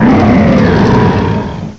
cry_incineroar.aif